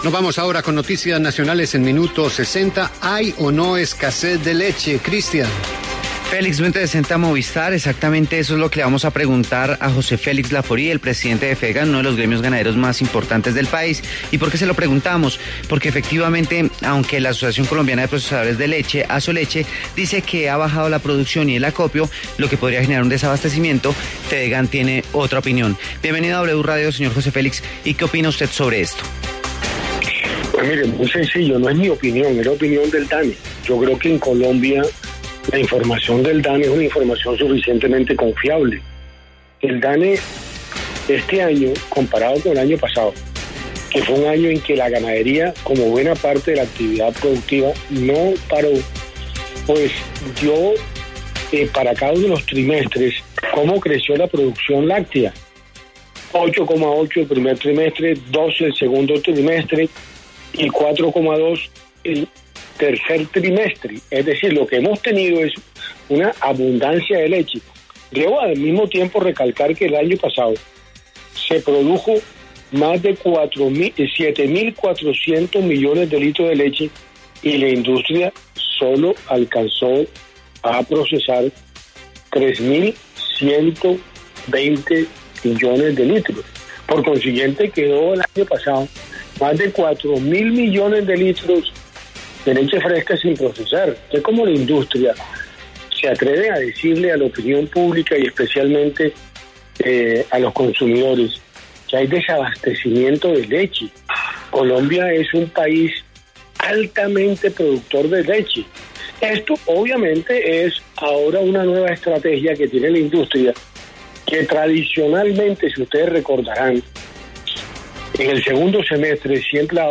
En La W, José Félix Lafaurie, presidente de Fedegán, fue crítico hacia las recientes declaraciones de Asoleche, quienes aseguran que Colombia podría tener desabastecimiento,